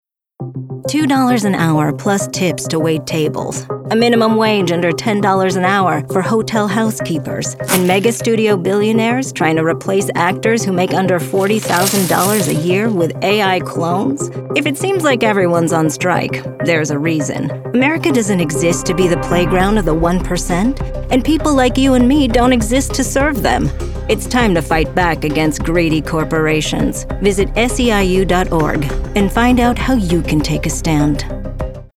Political Voice Demos
Professional Female Political Voiceover
• Home Studio